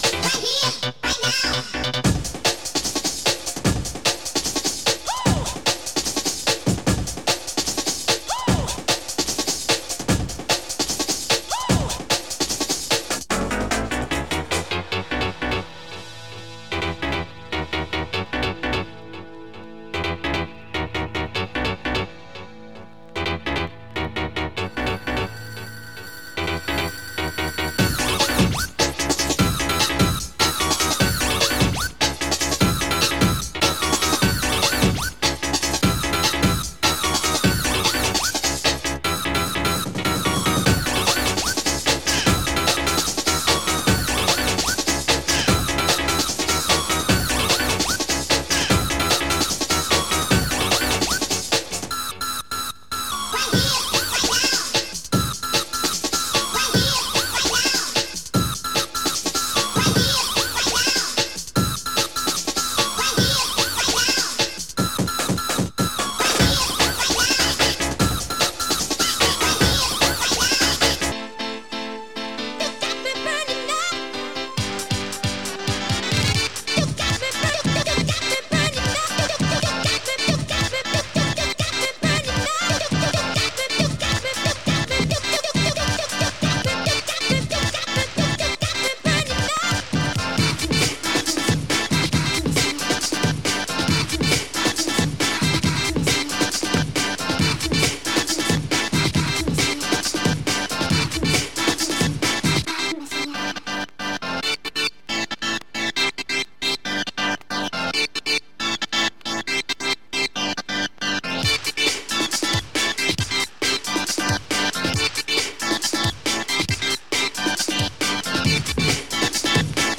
Surface marks and scratches vinyl plays close to VG+